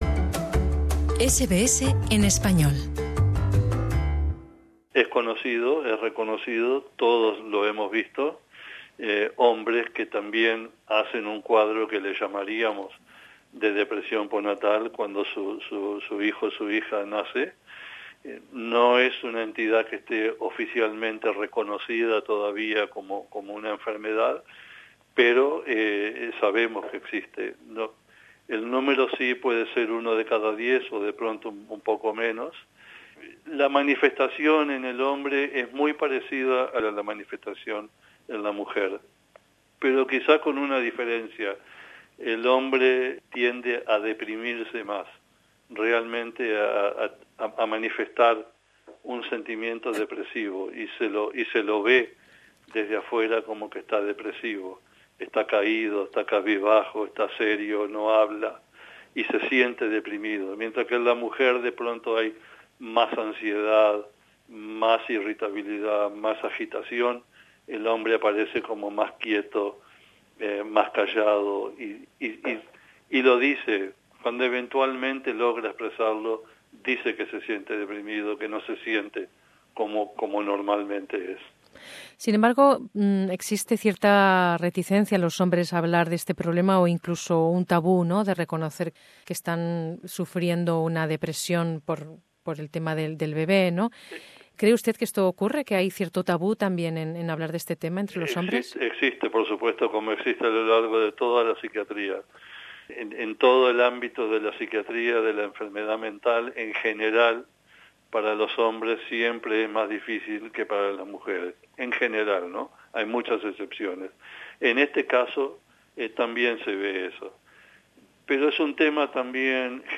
La afección puede ser tan grave que puede poner a la gente en riesgo de autolesionarse o incluso suicidarse. Escucha la entrevista